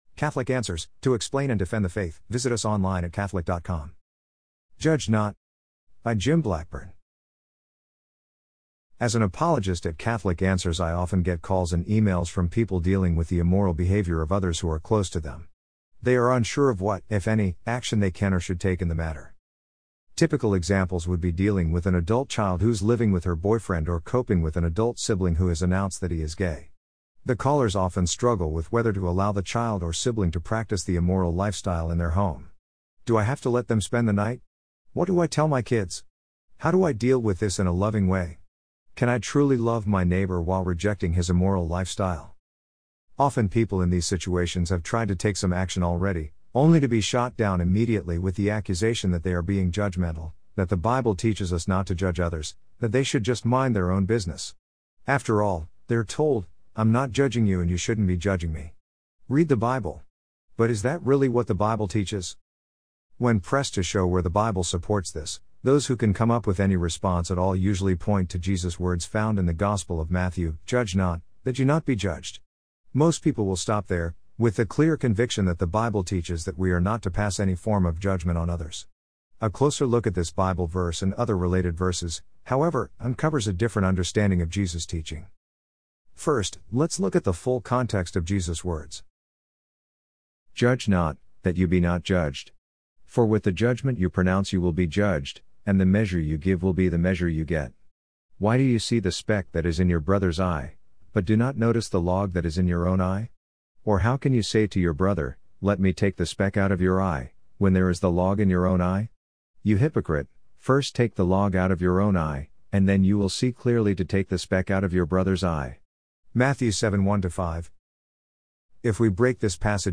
amazon_polly_6549.mp3